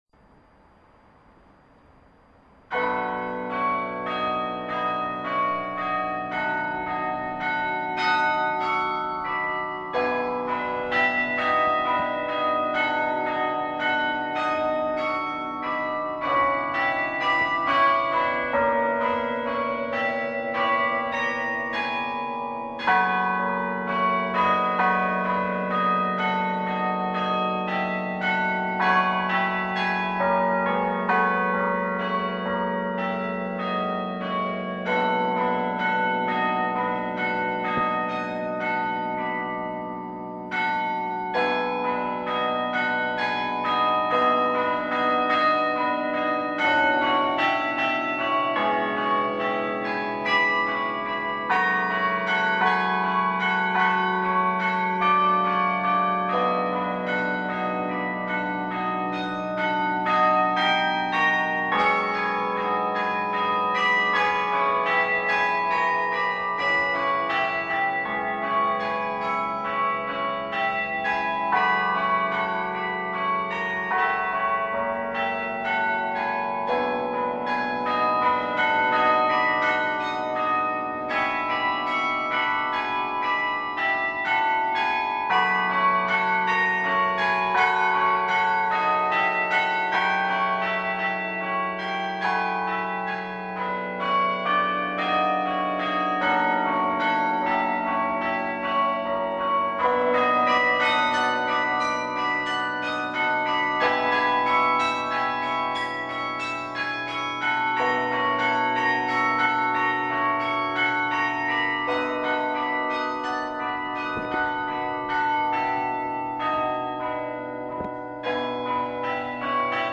Fleischmann Carillon Recital 13 June 2010 in St Colman’s Cathedral, Cobh